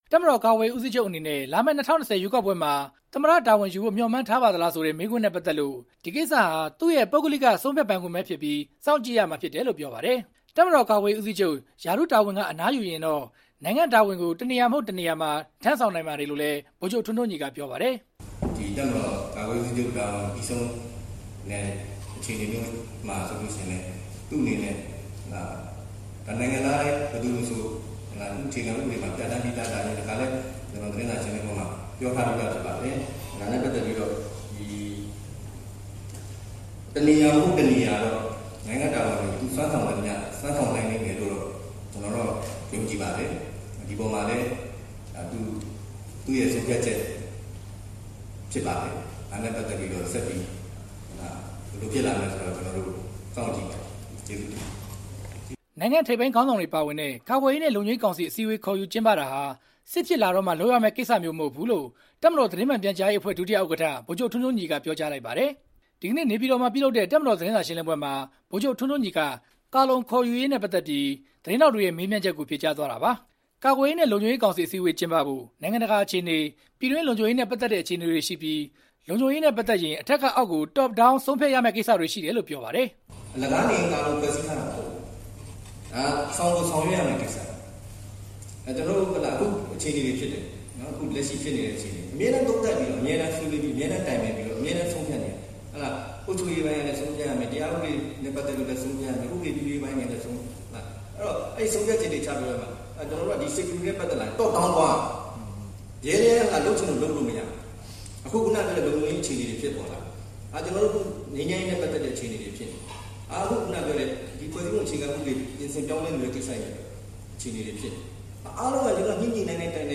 ဒီကနေ့ နေပြည်တော်မှာပြုလုပ်တဲ့ တပ်မတော် သတင်းစာရှင်းလင်းပွဲမှာ ဗိုလ်ချုပ် ထွန်းထွန်းညီက ကာလုံ ခေါ်ယူရေးနဲ့ ပတ်သက်ပြီး သတင်းထောက်တွေရဲ့ မေးမြန်းချက်ကို ဖြေကြားသွားတာပါ။